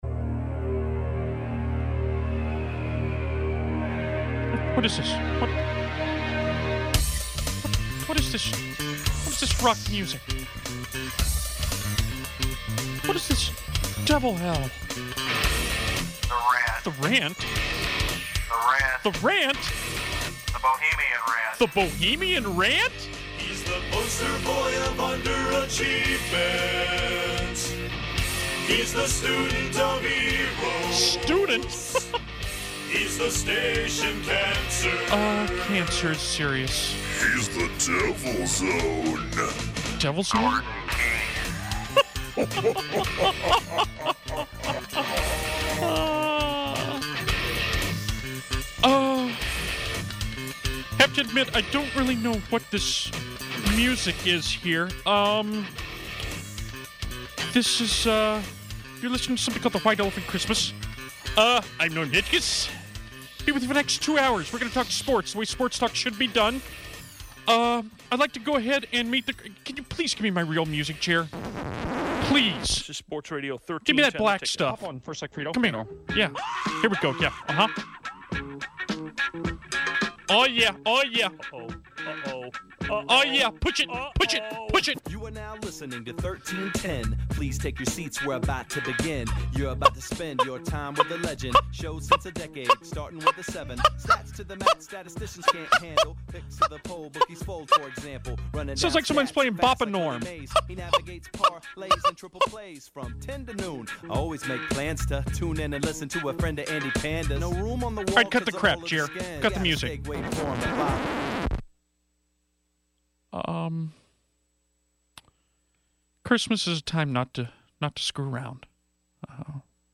reads his open letter to Santa on White Elephant.